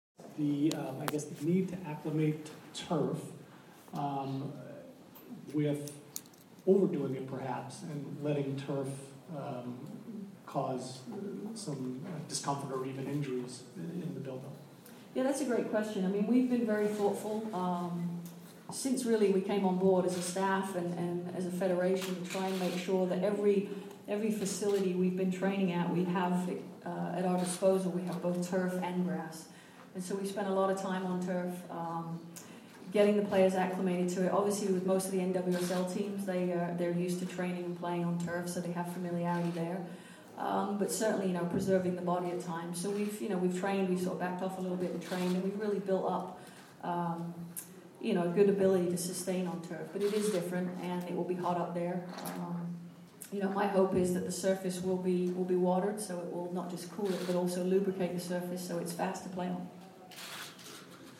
U.S. Head Coach Jill Ellis talks about how the team is acclimating to playing on turf